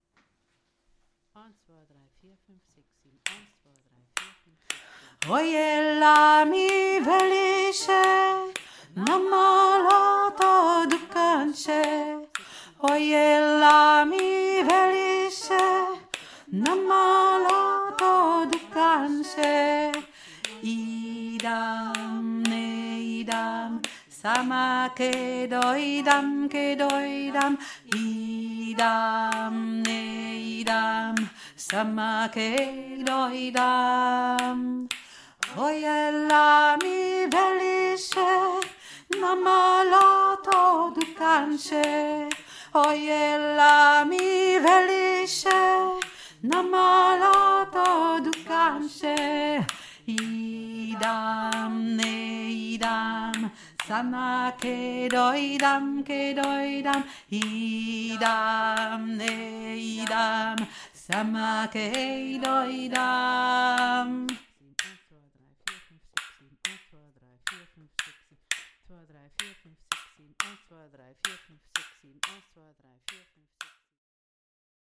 Oy e la mi Veliche (bulgarisches Lied)
Oy e la Hauptstimme